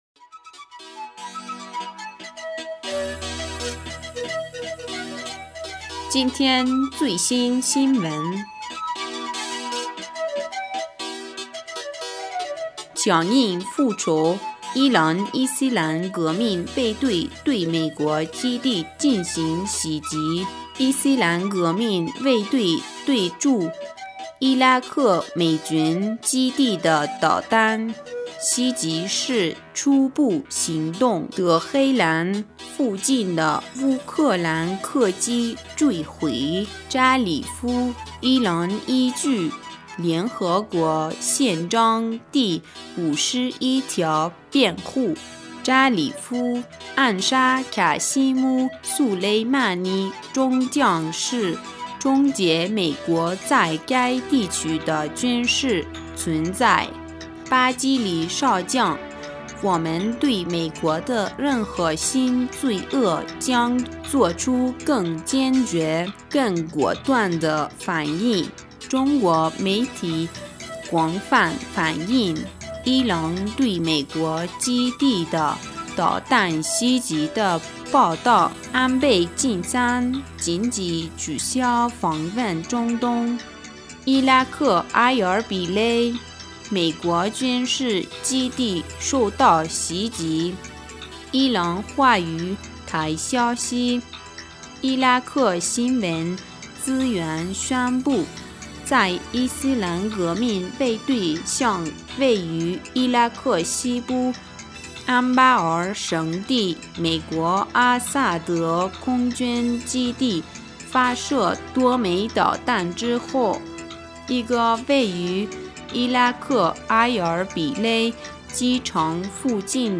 2020年1月8日 新闻